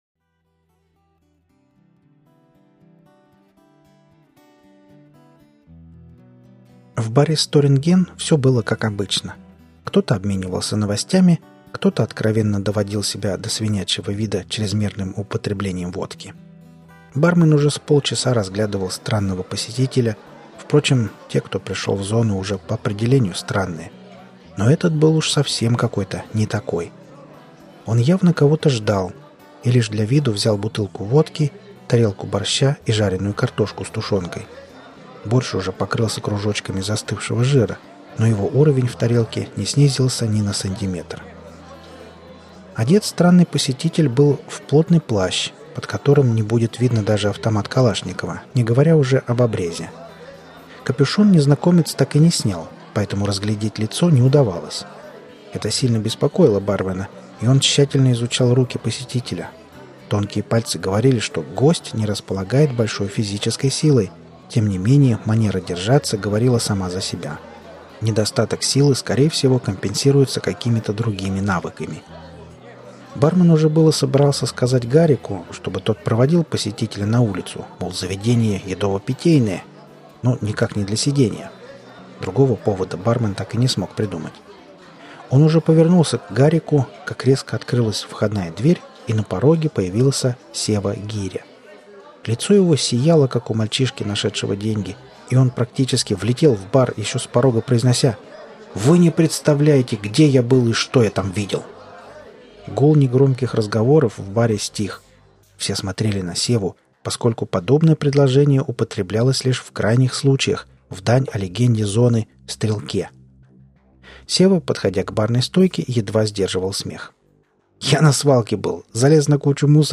Один год из жизни ботаника. Аудиокнига и текстовый вариант